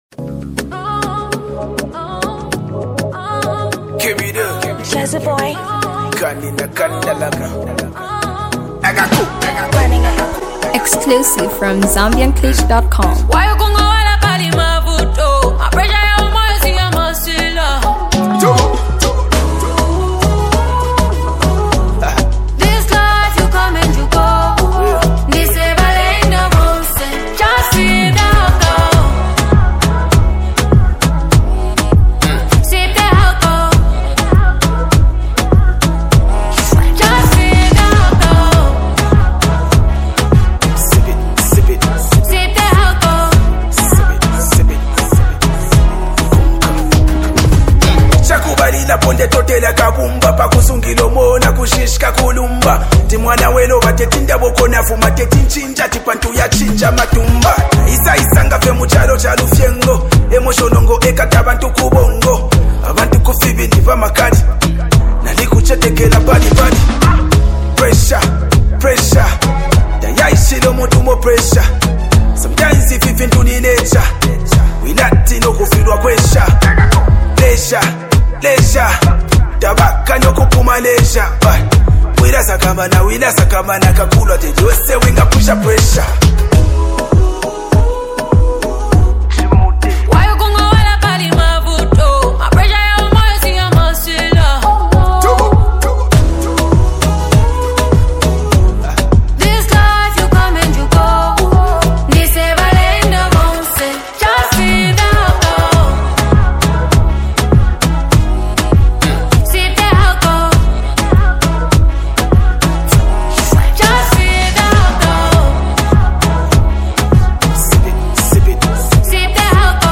country groove song
a new street anthem